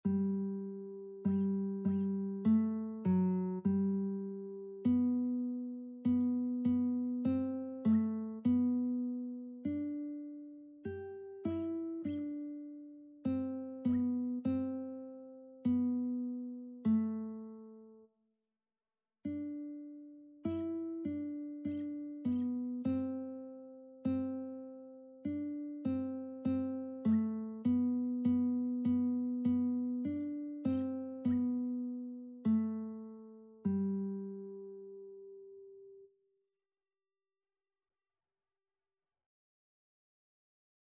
Christian Christian Lead Sheets Sheet Music Fairest Lord Jesus
G major (Sounding Pitch) (View more G major Music for Lead Sheets )
4/4 (View more 4/4 Music)
Traditional (View more Traditional Lead Sheets Music)